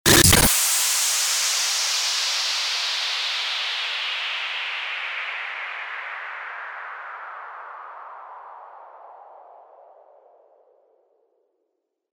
FX-832-STARTER-WHOOSH
FX-832-STARTER-WHOOSH.mp3